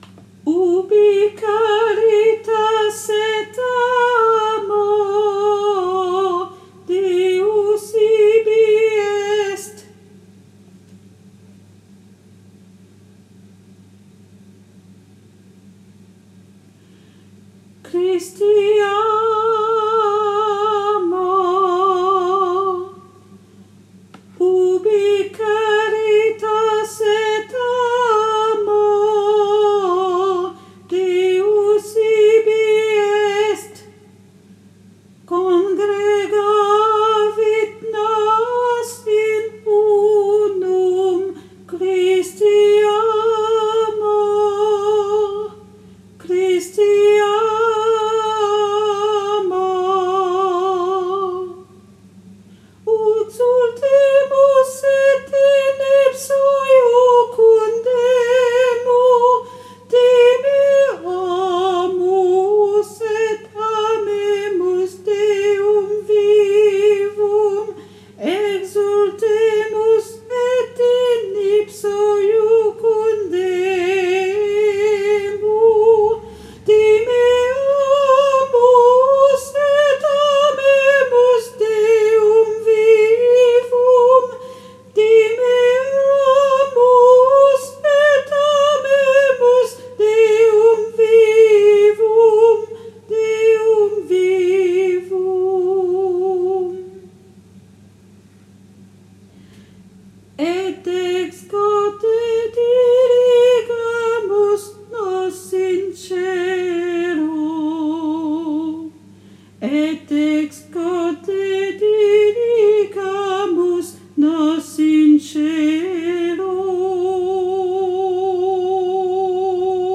MP3 versions chantées
Soprano